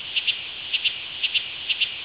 Cicada
Which animal makes the loud shrill sound heard outdoors?
A large insect called the Cicada produces this sound to attract females.
To attract females, the adult male cicada or Imago makes the loud sounds or "songs", using specialized abdominal structures called tymbals.
Click for a short sample song :
cicada.wav